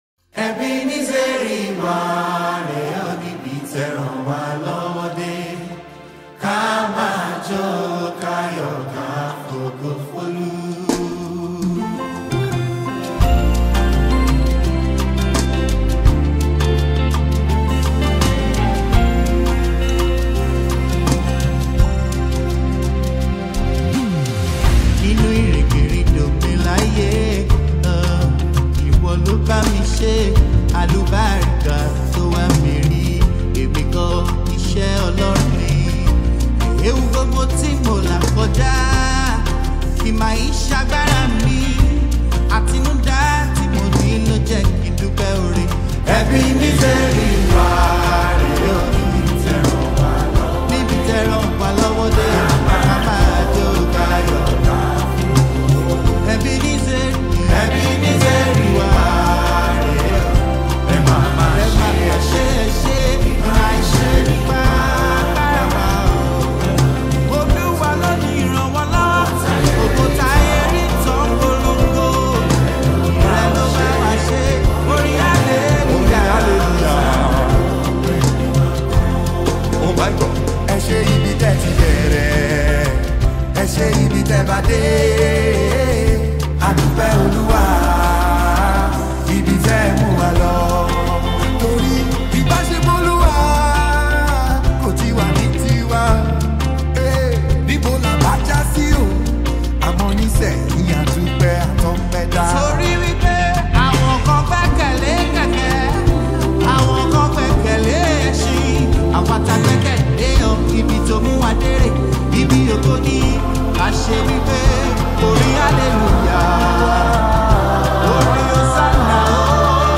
Gospel Gifted Multi-Talented artiste and Songwriter